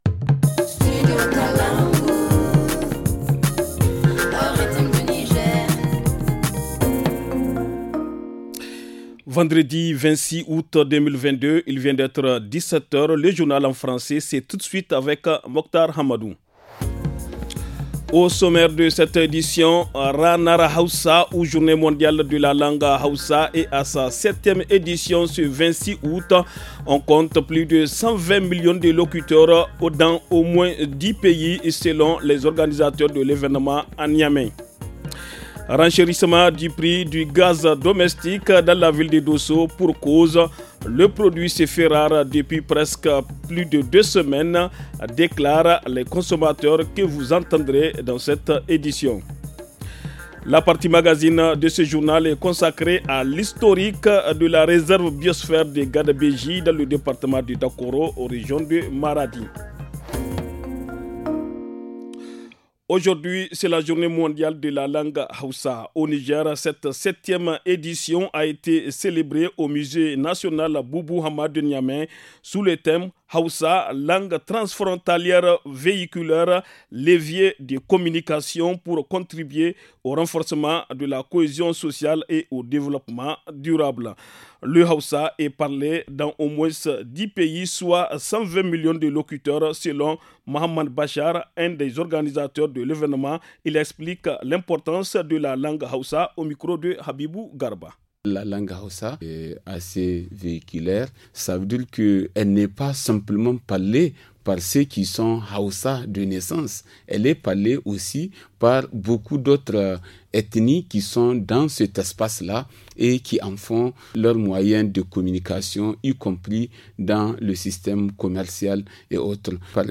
Le journal du 26 août 2022 - Studio Kalangou - Au rythme du Niger